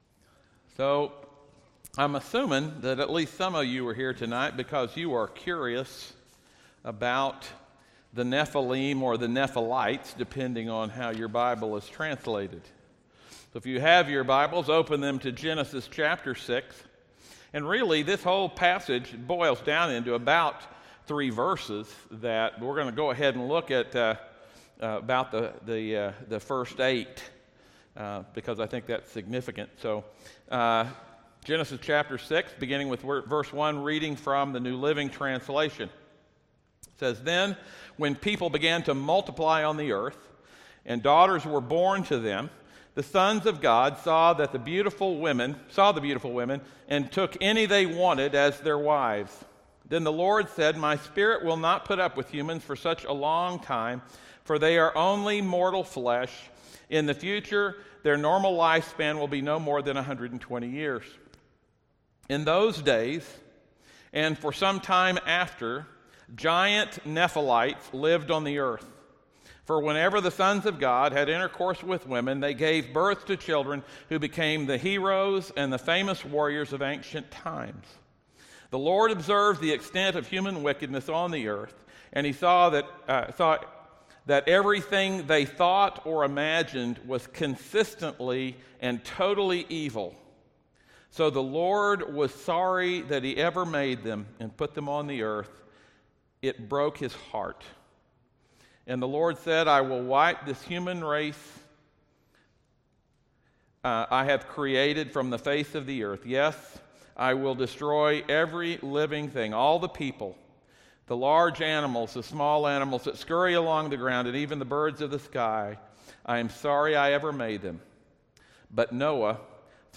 Series: 15 Chapters that Shape Everything Service Type: audio sermons « Hope In the Dark